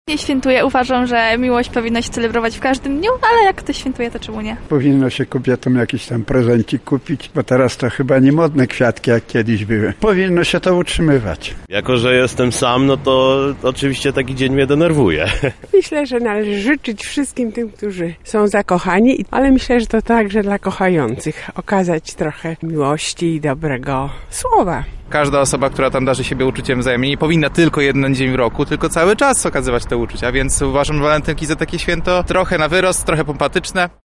Zapytaliśmy mieszkańców Lublina co sądzą o święcie zakochanych i czy planują je obchodzić:
Sonda Walentynki